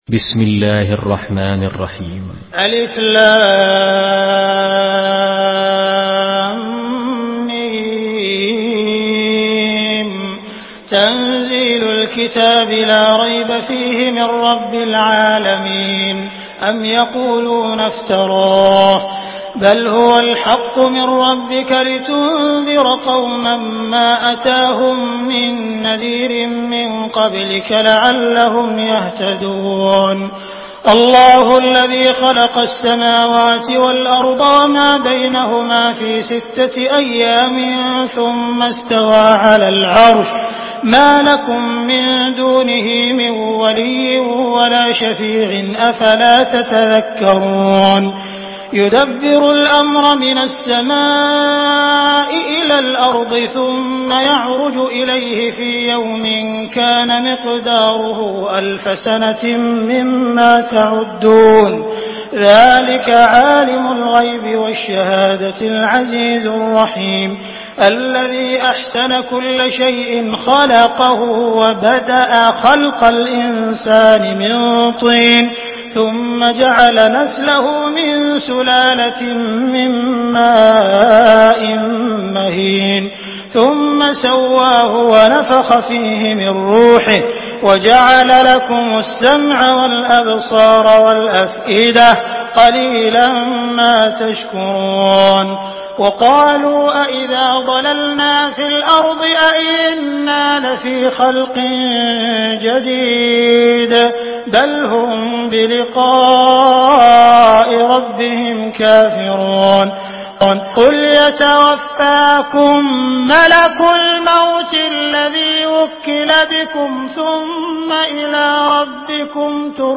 سورة السجدة MP3 بصوت عبد الرحمن السديس برواية حفص
مرتل